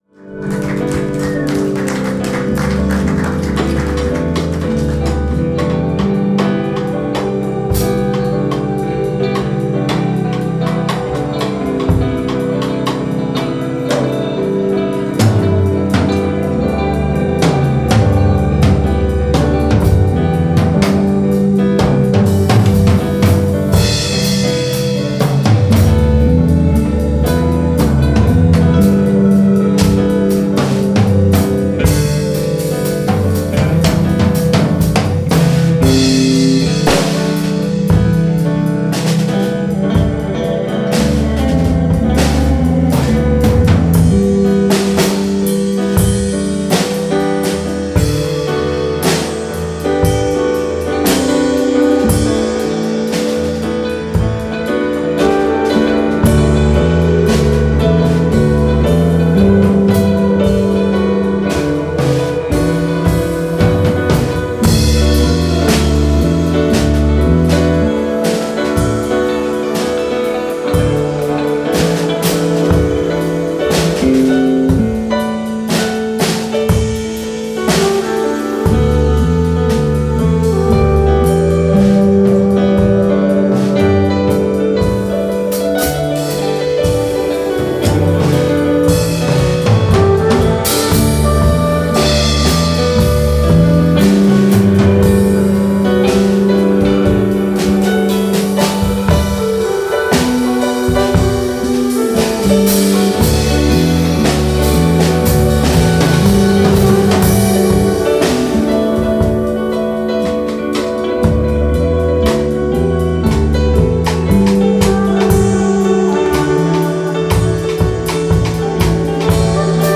live bei der Jazznacht Soest am 07.02.2026
Keyboard/Synthesizer
Flügelhorn/E-Gitarre
E-Gitarre
Bass/Samples
Schlagzeug